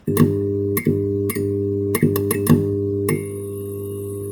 fail-sound.wav